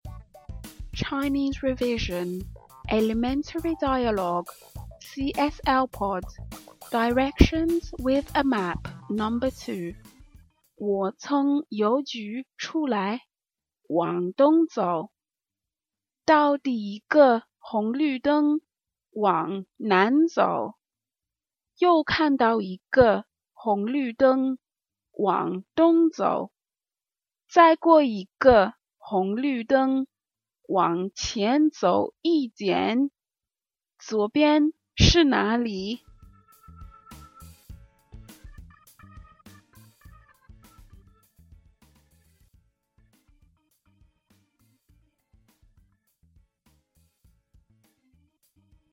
Today there are 2 dialogues (kind of) that will introduce some vocabulary words that crop up when asking for directions. If you get these down you will have a much better time finding your way around China or any other Chinese Mandarin speaking country/place.